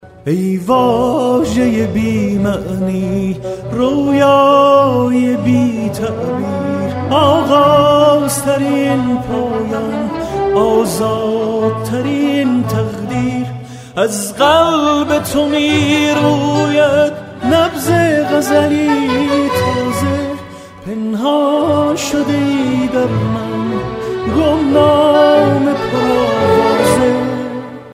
رینگتون موبایل زیبا و با کلام و سنتی